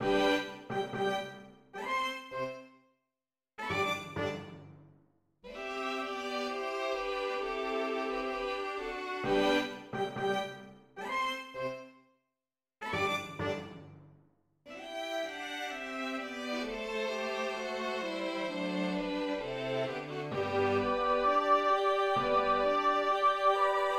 1. Allegro spiritoso, in Sol maggiore, tempo 4/4.